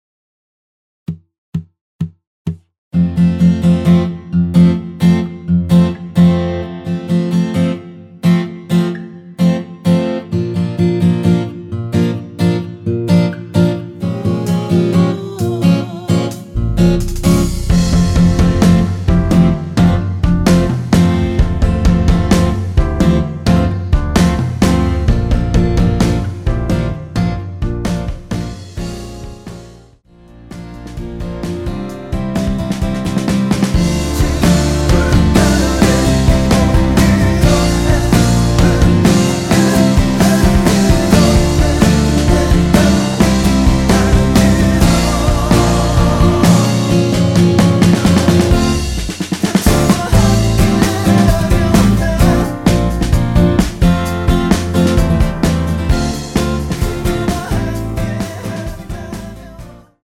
전주 없이 시작하는 곡이라 4박 카운트 넣어 놓았습니다.(미리듣기 확인)
원키 코러스 포함된 MR입니다.
앞부분30초, 뒷부분30초씩 편집해서 올려 드리고 있습니다.